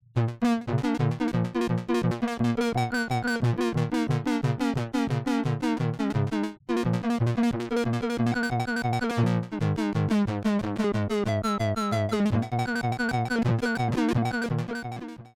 random autobend